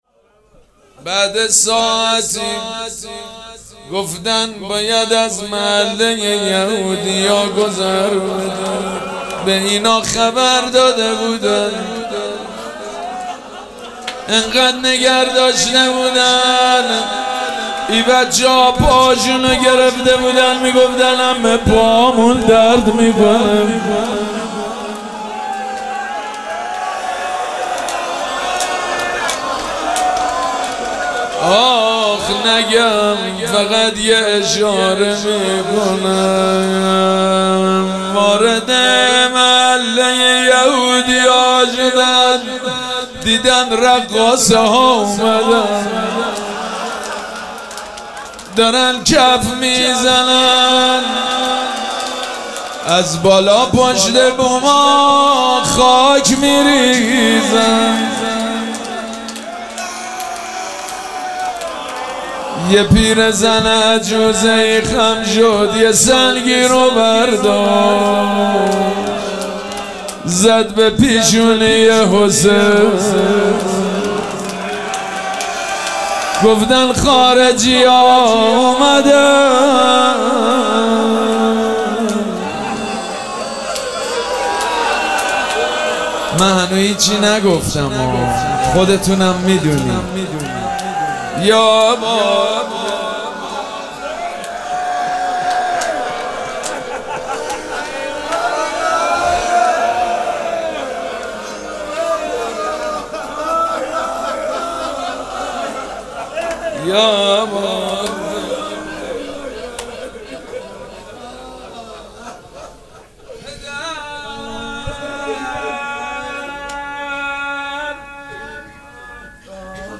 مراسم عزاداری شب شهادت حضرت رقیه سلام الله علیها
روضه
مداح